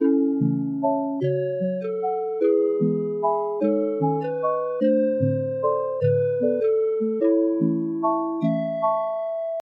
滤波器合成器
描述：Fm合成器循环
Tag: 100 bpm Ambient Loops Synth Loops 1.62 MB wav Key : C